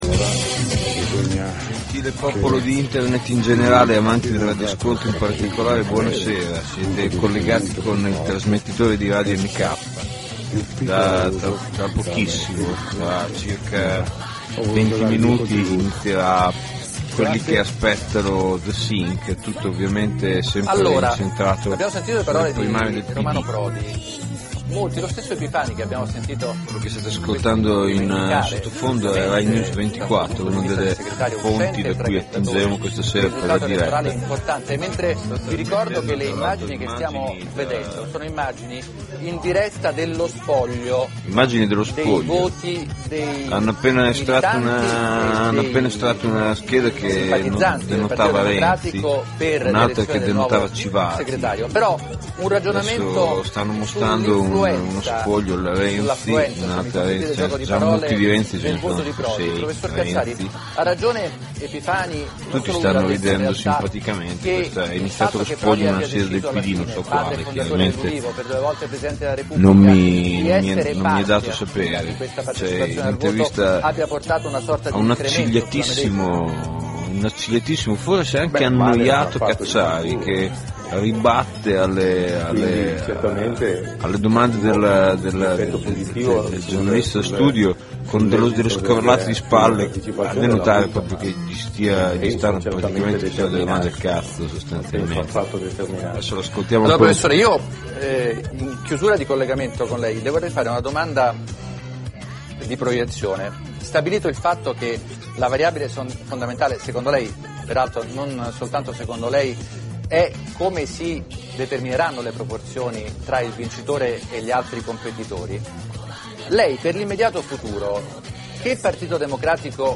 Intro a “The Winner Is…”, una buona oretta del programma sperimentale YOU ARE LISTENING TO RADIOTELEVISIONE ITALIANA, space chillout + rai news24